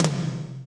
Index of /90_sSampleCDs/Northstar - Drumscapes Roland/TOM_Toms 1/TOM_F_R Toms x